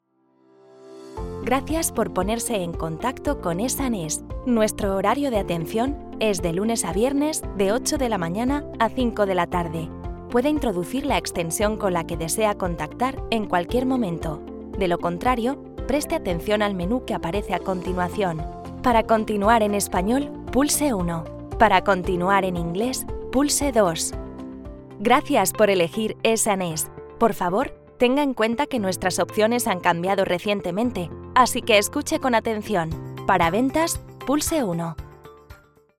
Commercial, Young, Natural, Friendly, Warm
E-learning